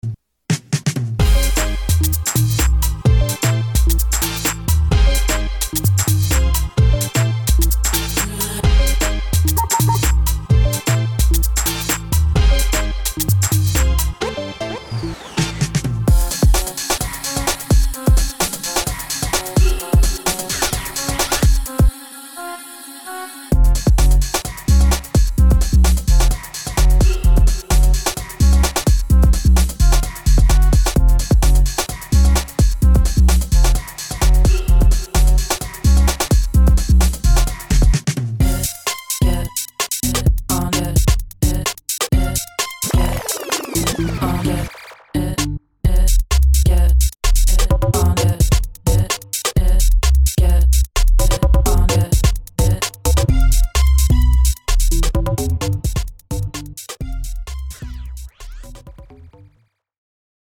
Uk Garage